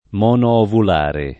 vai all'elenco alfabetico delle voci ingrandisci il carattere 100% rimpicciolisci il carattere stampa invia tramite posta elettronica codividi su Facebook monoovulare [ m q no-ovul # re o m q novul # re ] → monovulare